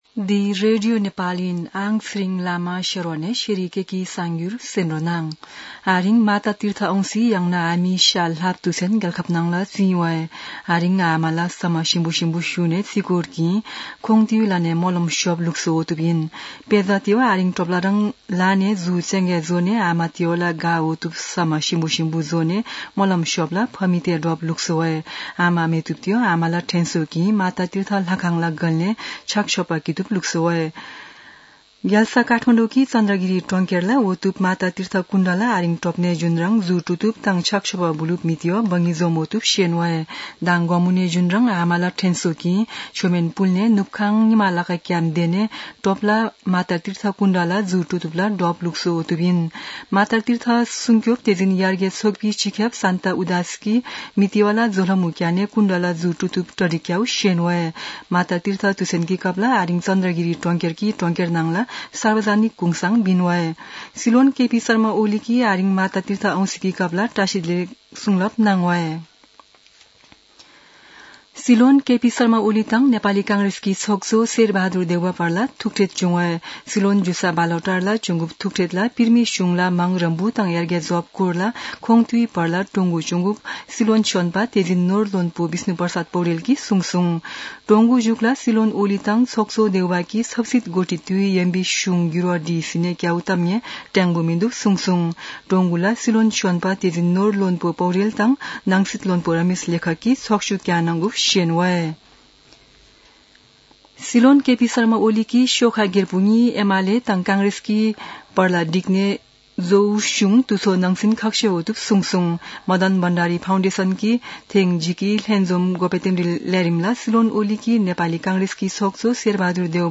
शेर्पा भाषाको समाचार : १४ वैशाख , २०८२
sharpa-news-1-3.mp3